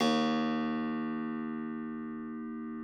53l-pno03-D0.aif